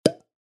Звуки пробки бутылки
Звук открывающейся бутылки вина